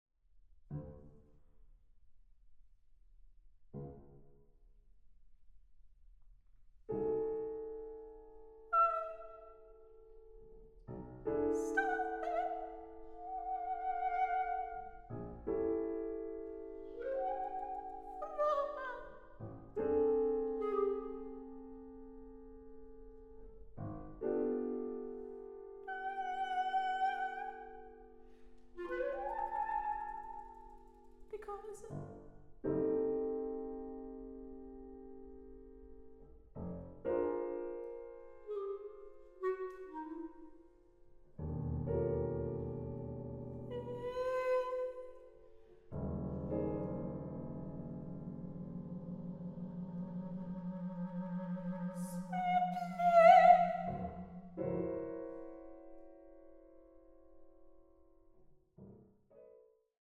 soprano
clarinet
piano